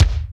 27.04 KICK.wav